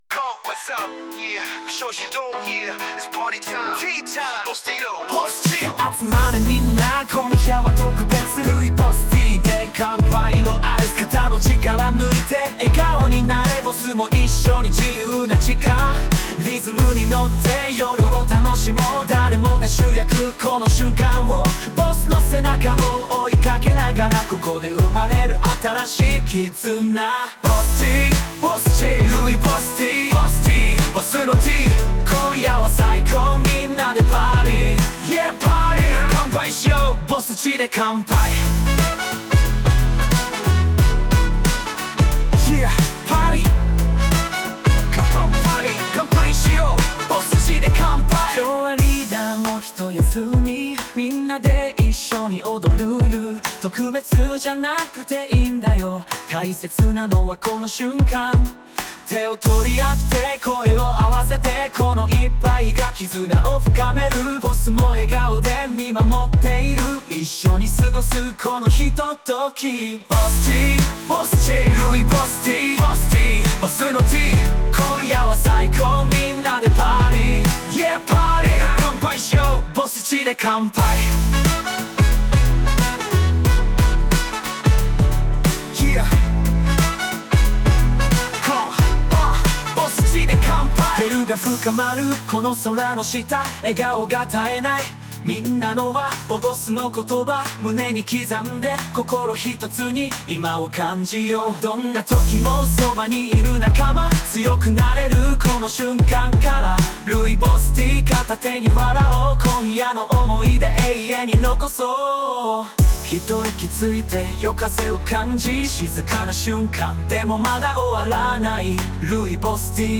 うまく生成できずにポップな曲調に。